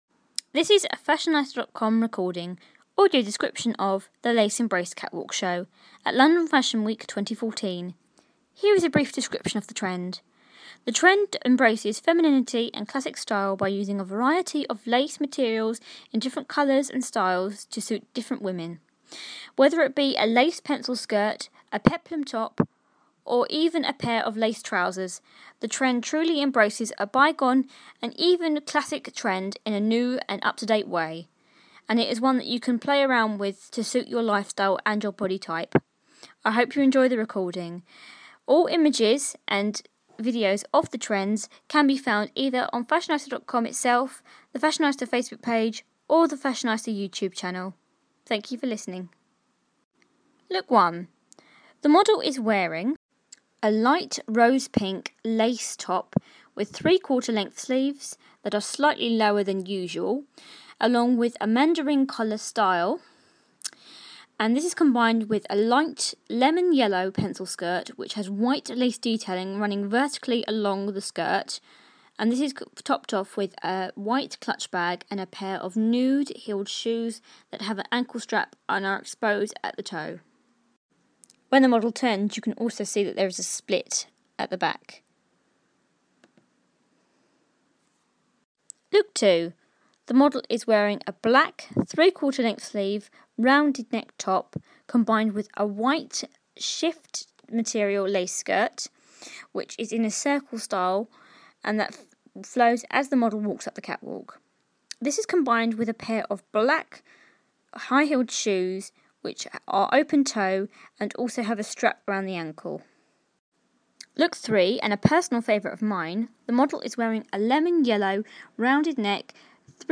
Audio Description of The Lace Embrace Catwalk Show